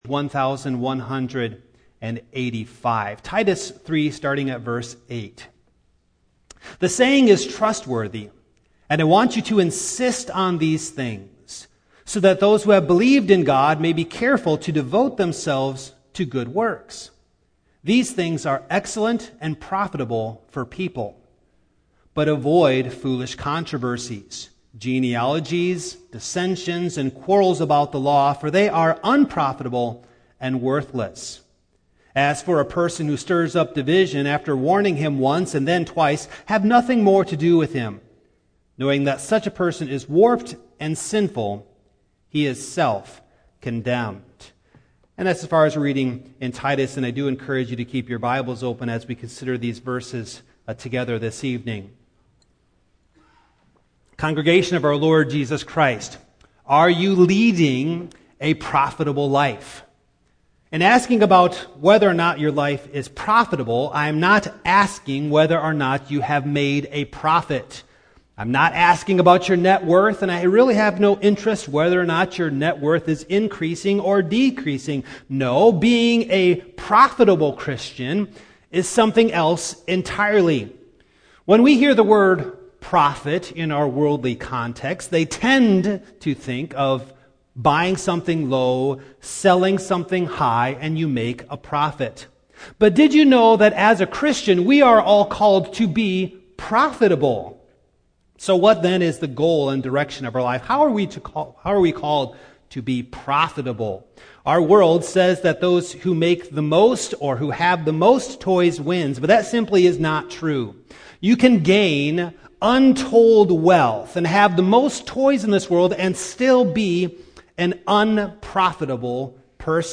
The Book of Titus Passage: Titus 3:8-11 Service Type: Evening Download Files Notes « How Bad is our Corruption?